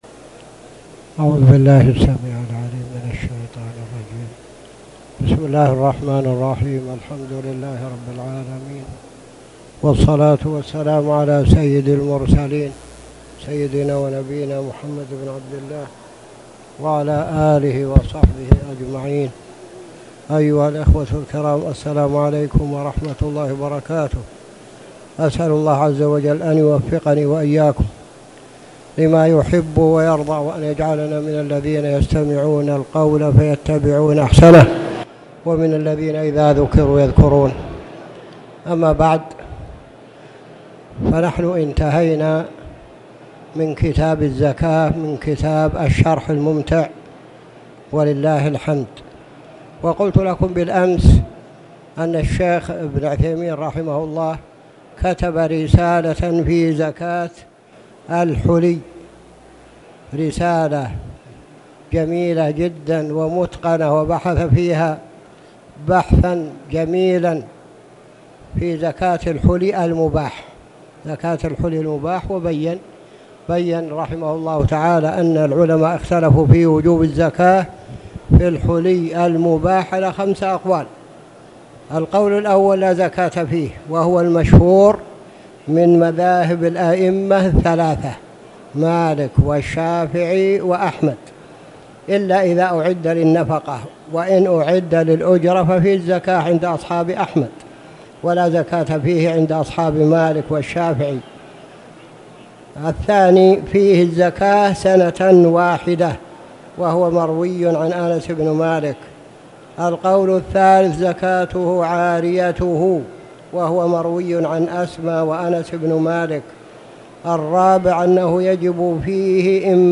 تاريخ النشر ١٥ رجب ١٤٣٨ هـ المكان: المسجد الحرام الشيخ